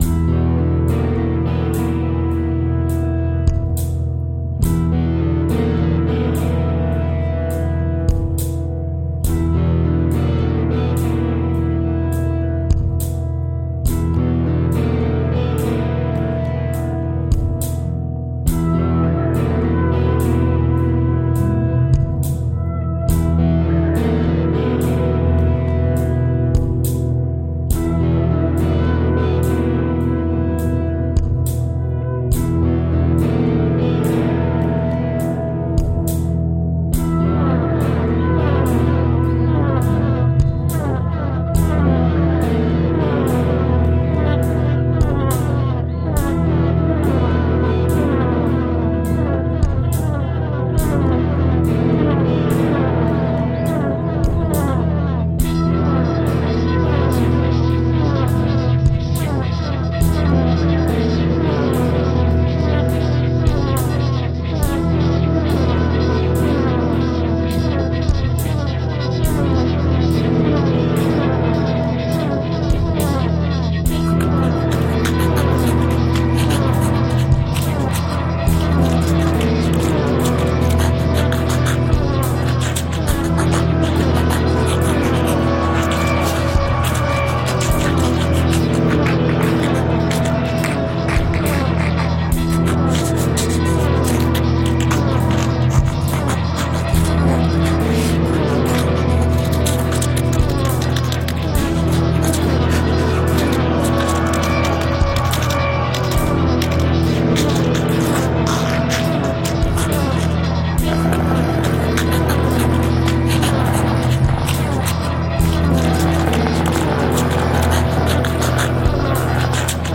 The second song, "Gorgons Have Landed," is an instrumental.